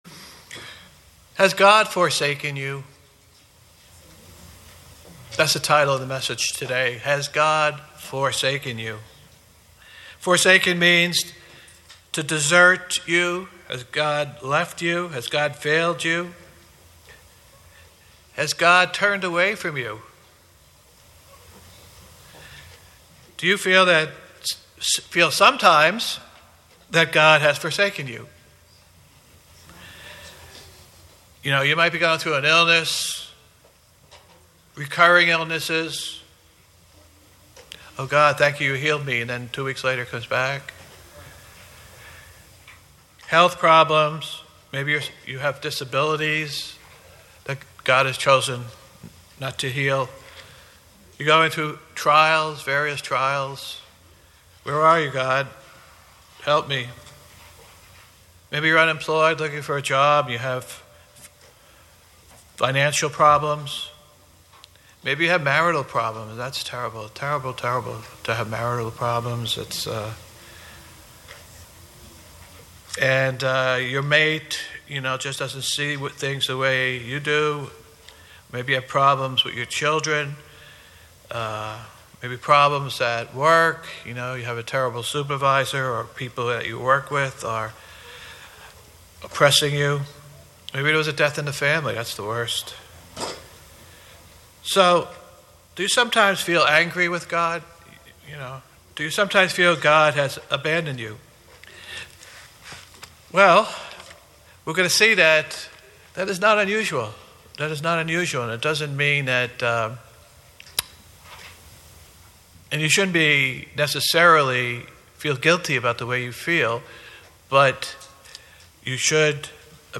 Sermons
Given in New York City, NY New Jersey - North